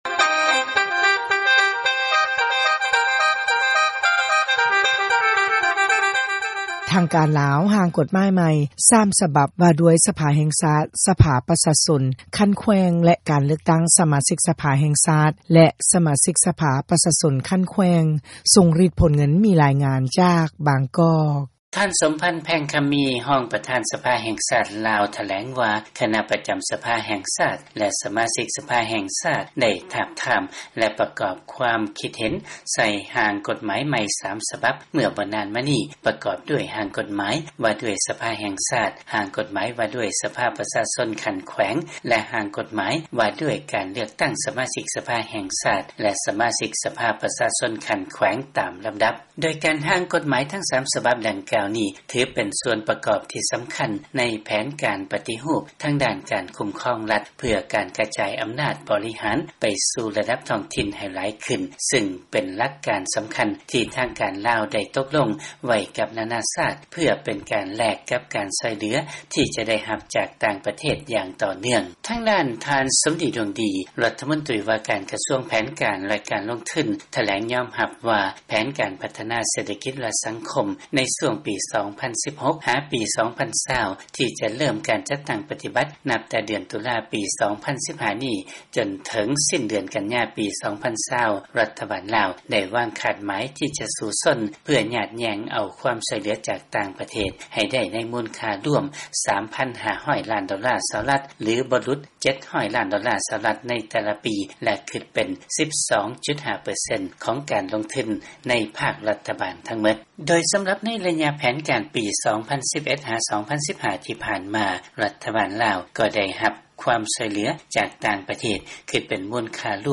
ຟັງລາຍງານ ທາງການລາວ ຮ່າງກົດໝາຍໃໝ່ 3 ສະບັບ ກ່ຽວກັບ ການເລືອກຕັ້ງ ສະມາຊິກສະພາ.